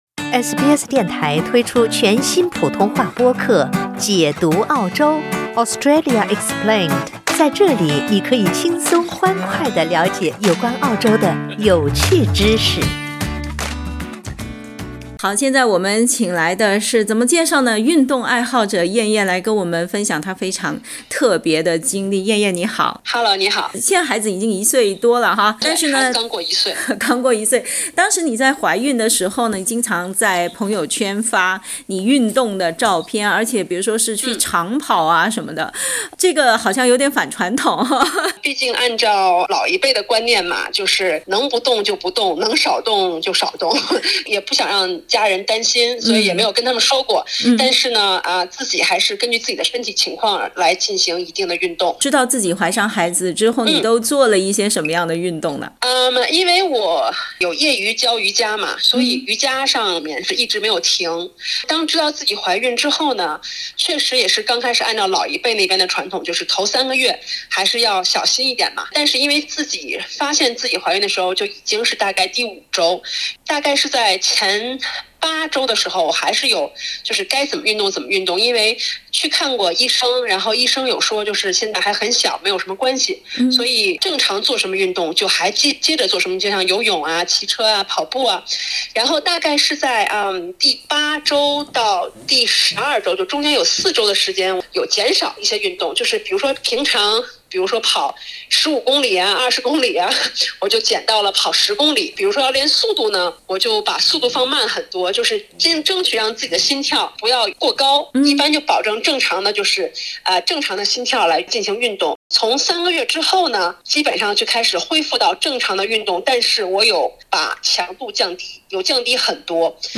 安全地进行孕期运动需要哪些条件？（点击图片收听完整采访）